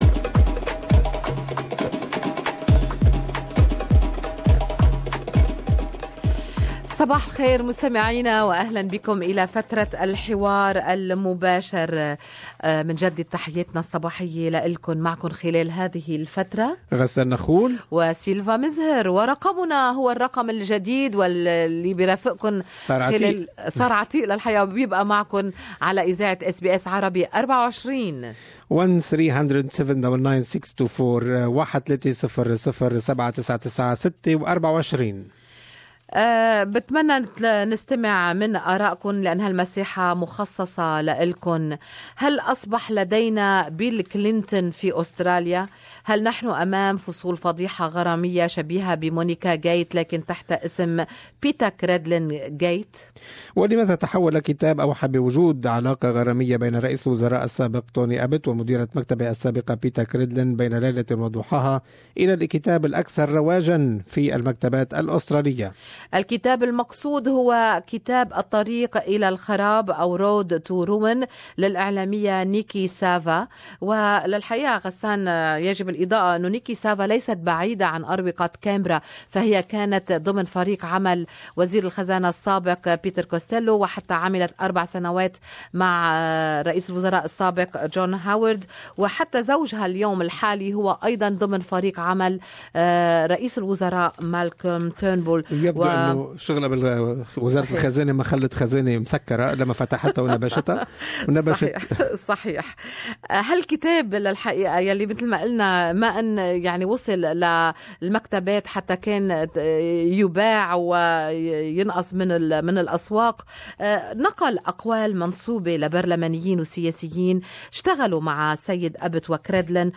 ولماذا تحوّل كتاب أوحى بوجود علاقة غرامية بين رئيس الوزراء السابق طوني آبوت ومديرة مكتبه السابقة بيتا كريدلن , بين ليلة وضحاها , إلى الكتاب الأكثر رواجاً في المكتبات الأسترالية ؟؟؟الكتاب المقصود هنا هو كتاب :" الطريق إلى الدمار" للإعلامية نيكي سافا اراء المستمعين في فقرة الحوار المباشر